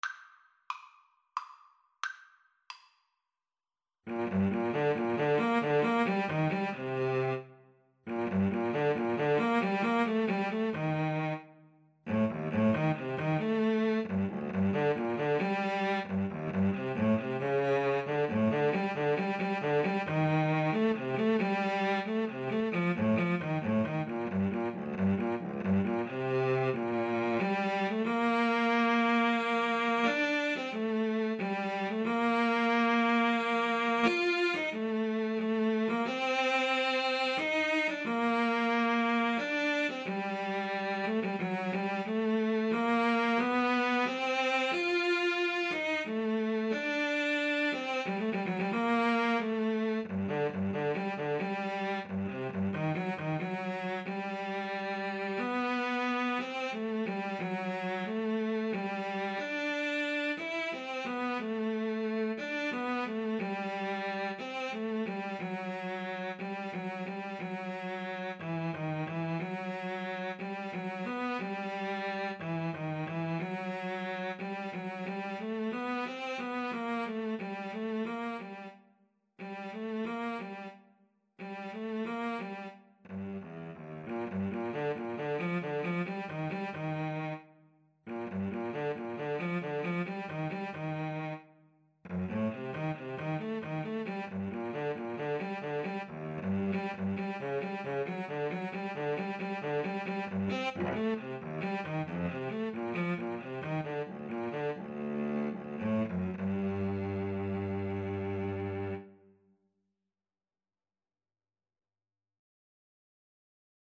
Poco Allegretto = 90
Classical (View more Classical Trumpet-Cello Duet Music)